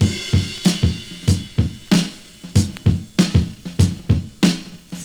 Drum loops
Original creative-commons licensed sounds for DJ's and music producers, recorded with high quality studio microphones.
95-bpm-drum-beat-f-sharp-key-4hd.wav